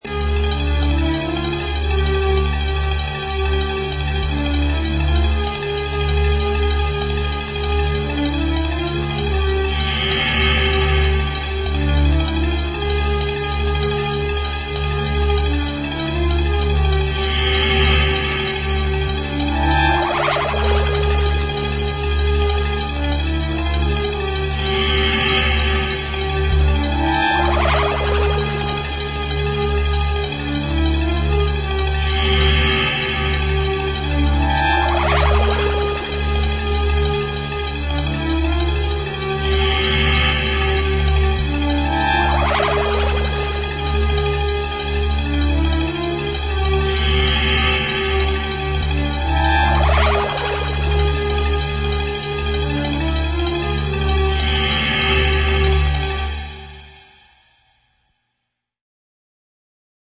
foreboding introduction